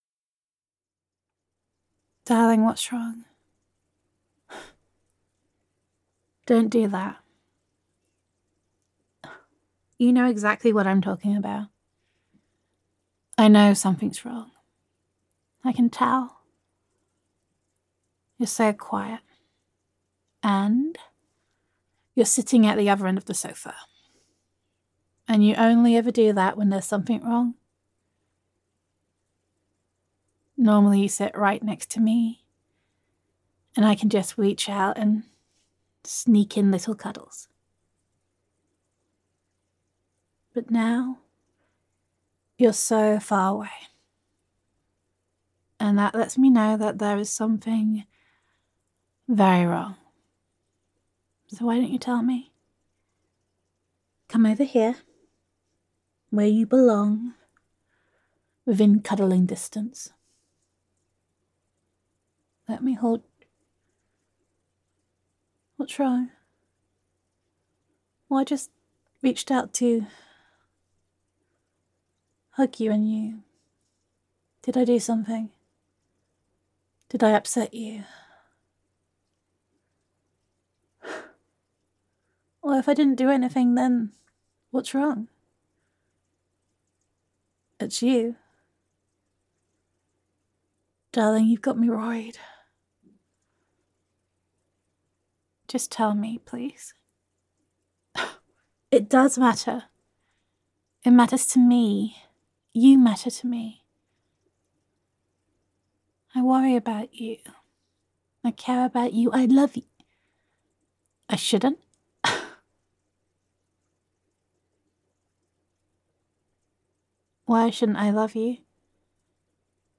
[F4A] Listen to Me [I Know Something Is Wrong][I Know You][Darling, You Give Me More Than You Know][Give and Take][Appreciation][Sweetness][Reassurance][Comfort][Gender Neutral][Loving Girlfriend Roleplay]